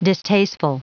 Prononciation du mot distasteful en anglais (fichier audio)